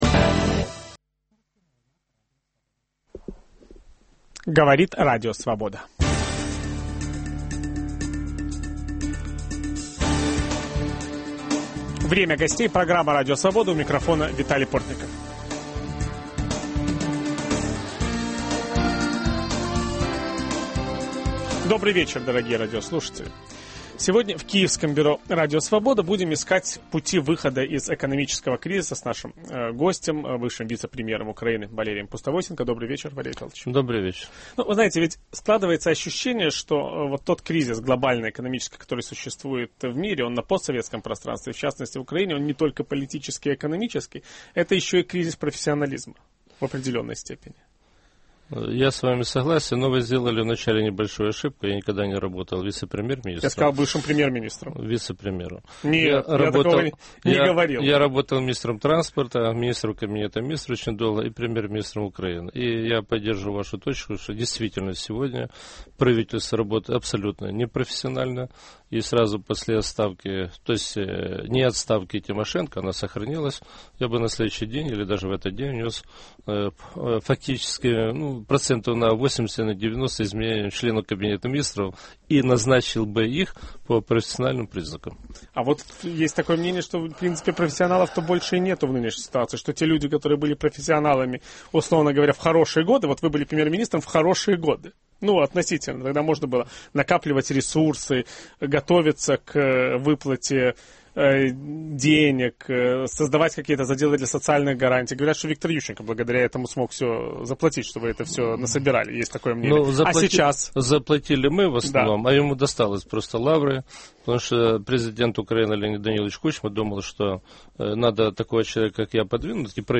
Что такое консолидация национальных элит? Виталий Портников беседует с бывшим премьер-министром Украины Валерием Пустовойтенко